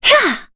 Yuria-Vox_Attack4_kr.wav